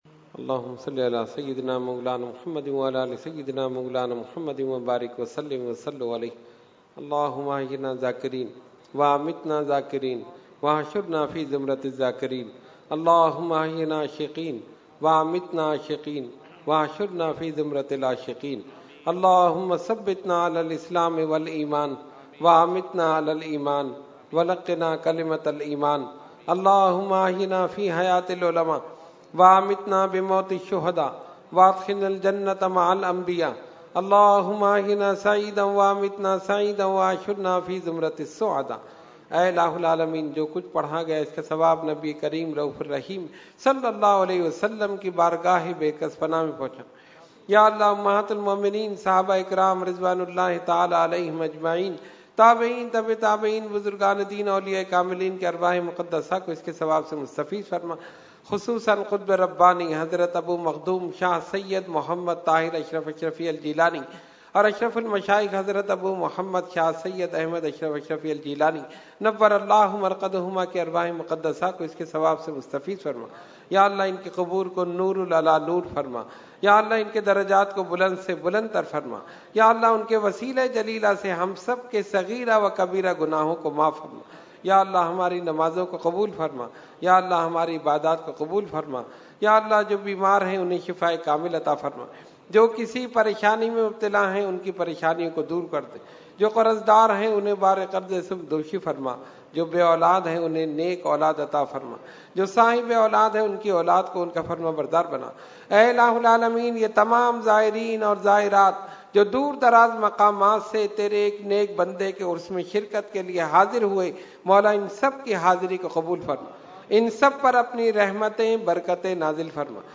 Category : Dua | Language : UrduEvent : Urs Qutbe Rabbani 2019